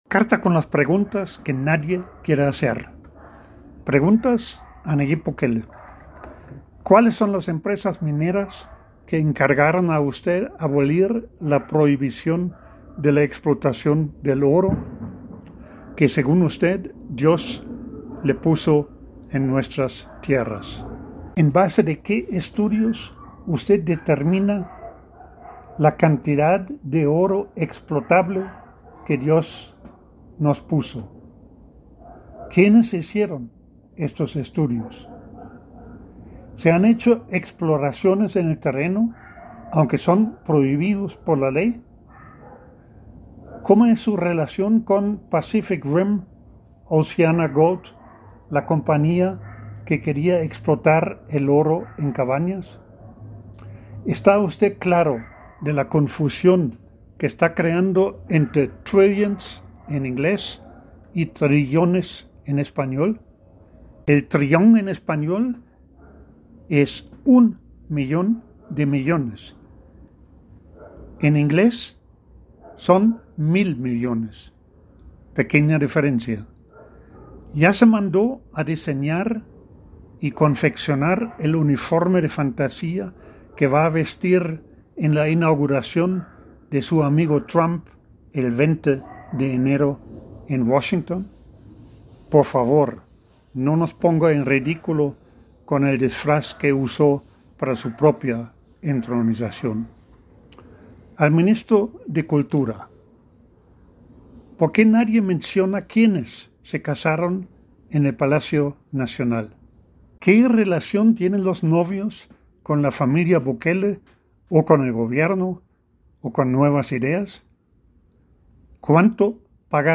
El audio en la voz del autor: